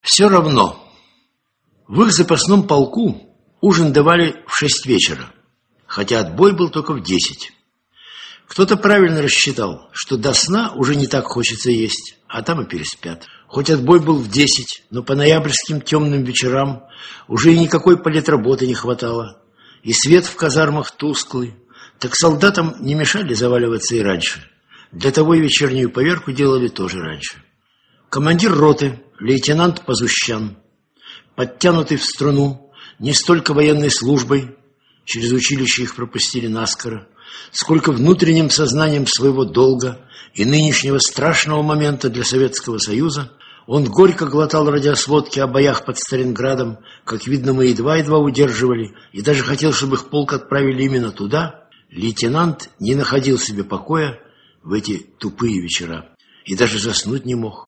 Адлиг Швенкиттен Автор Александр Солженицын Читает аудиокнигу Александр Солженицын.